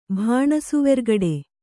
♪ bhāṇasuvergaḍe